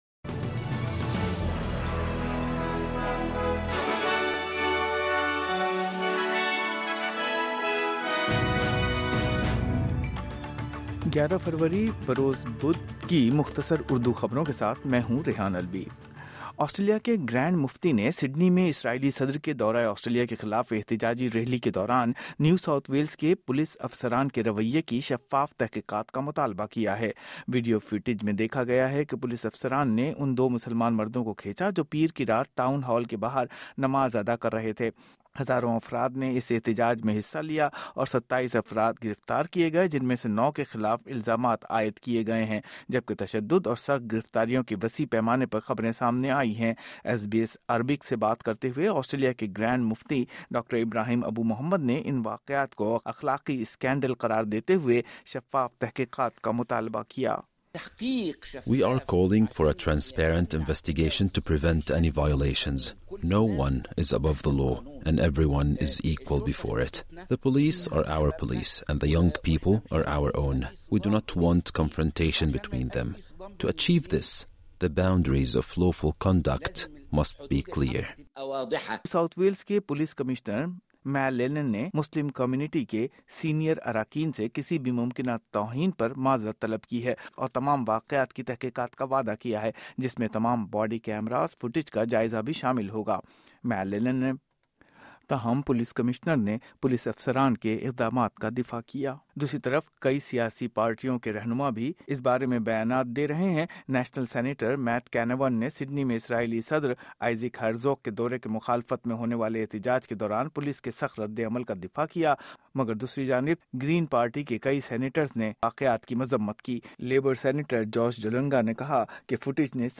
مختصر خبریں: بدھ 11 فروری 2026